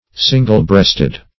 Single-breasted \Sin"gle-breast`ed\, a.